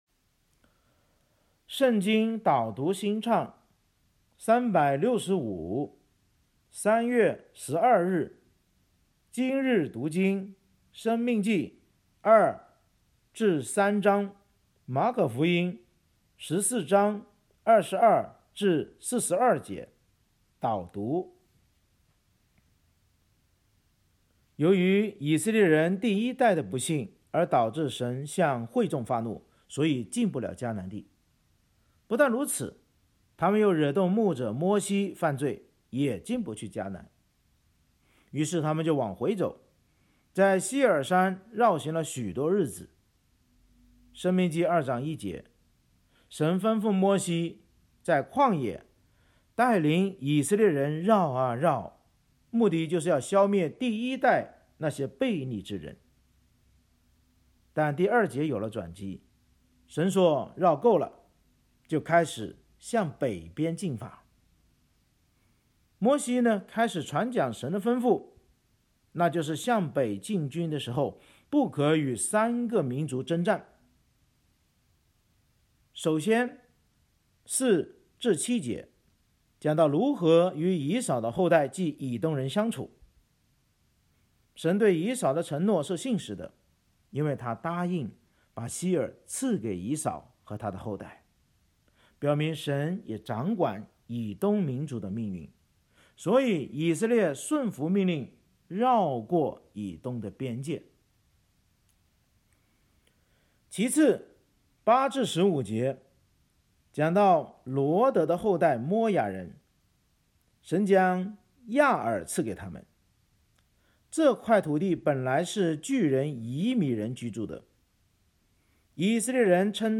【经文朗读】